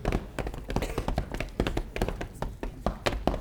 FOOTSTOMP2-R.wav